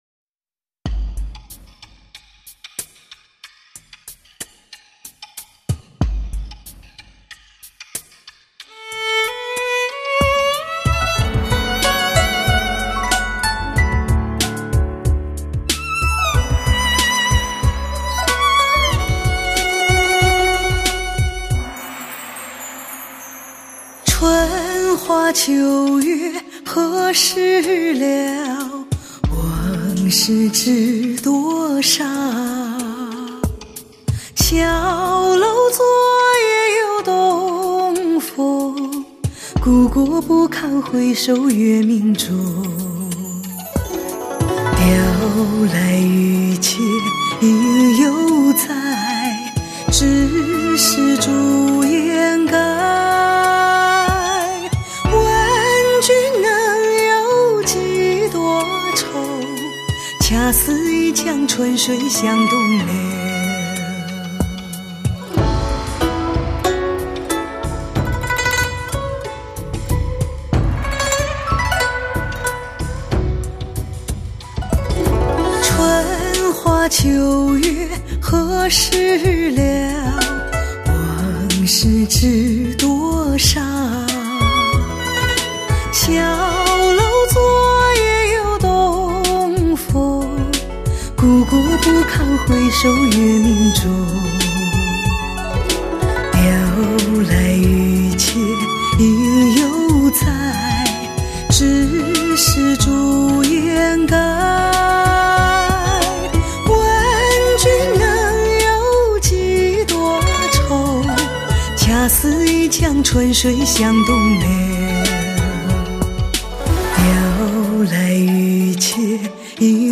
温婉可人，时间总在不经意间流逝，